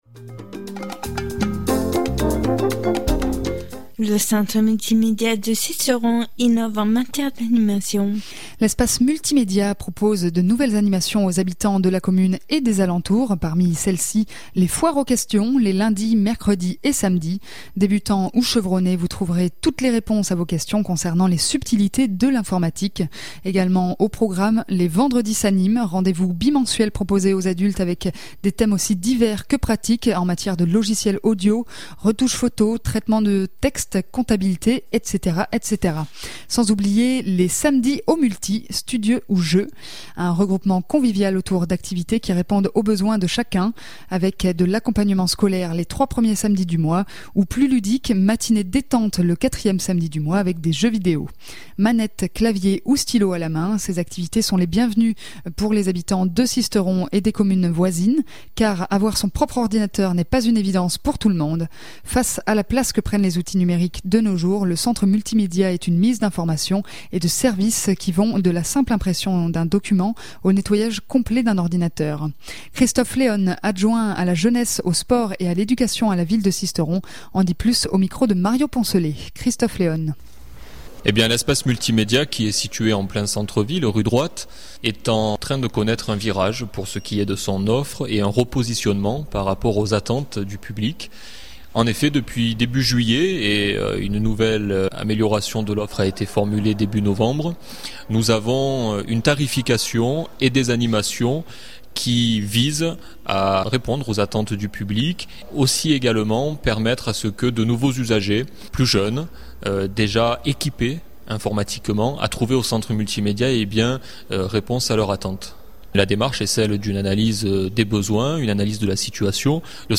Christophe Leone, Adjoint à la Jeunesse au Sport et à l’Education à la Ville de Sisteron en dit plus au micro